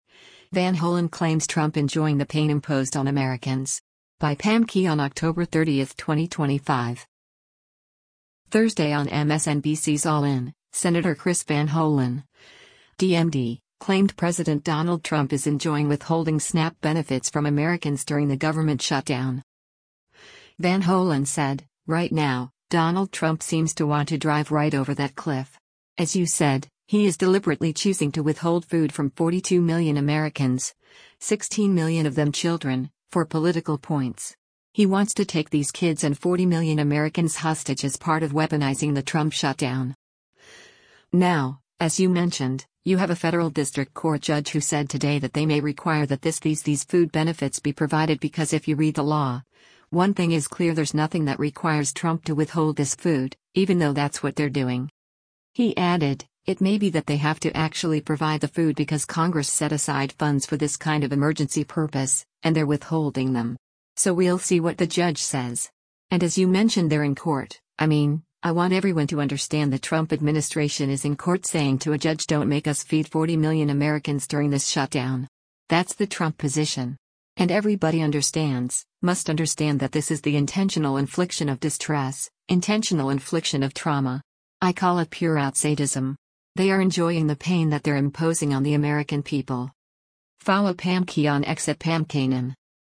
Thursday on MSNBC’s “All In,” Sen. Chris Van Hollen (D-MD) claimed President Donald Trump is enjoying withholding SNAP benefits from Americans during the government shutdown.